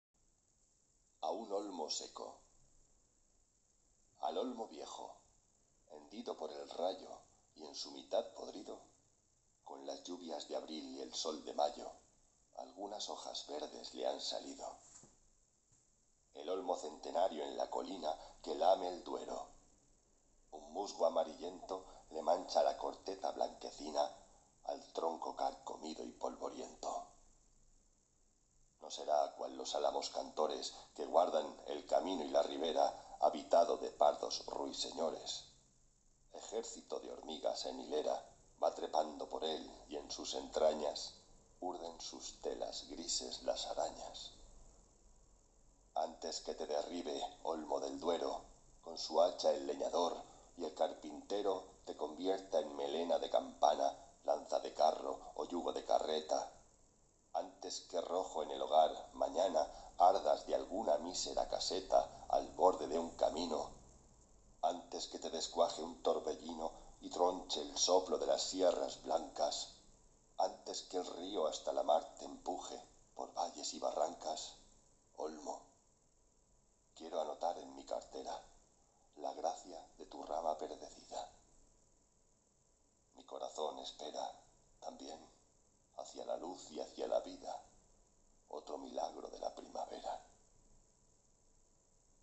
Escucha ahora este poema de Antonio Machado (1875-1939). En él el poeta nos describe un olmo que casi ha quedado seco del todo.